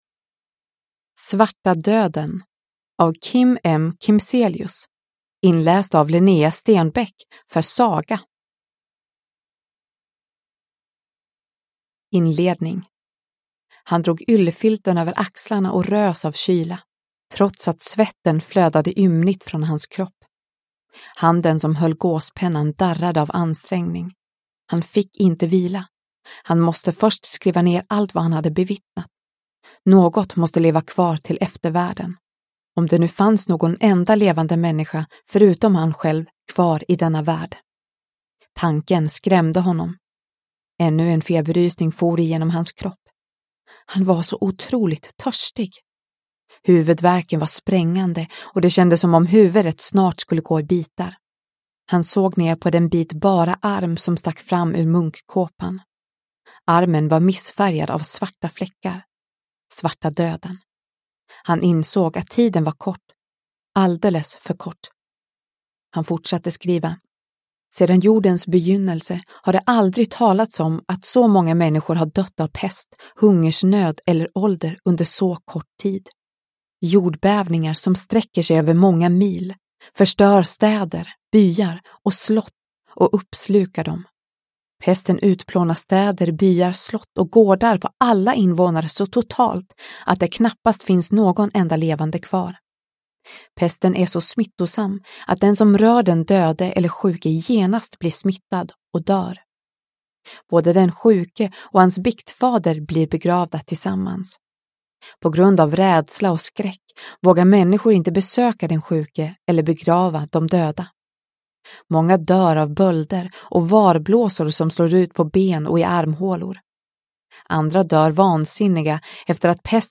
Svarta döden – Ljudbok